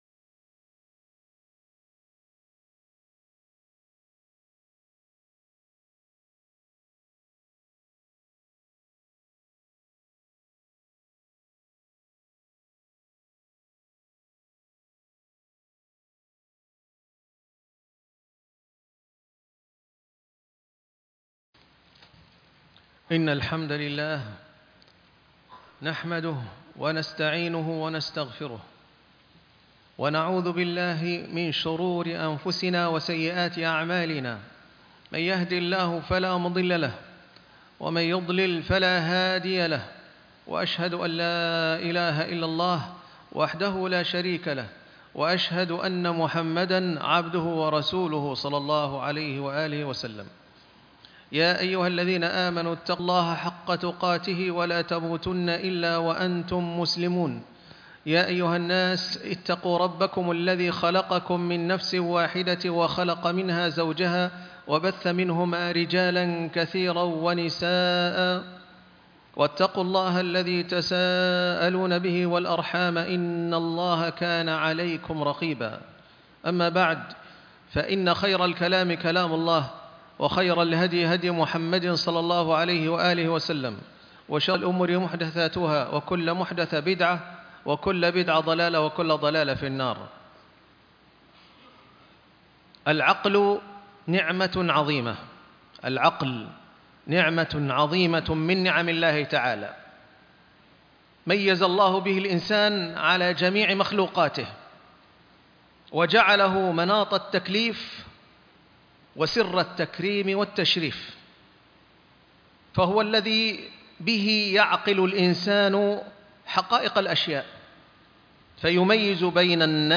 حفظ العقل - خطب الجمعة